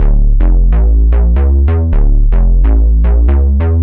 cch_bass_loop_scale_125_Dm.wav